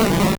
Cri de Chétiflor dans Pokémon Or et Argent.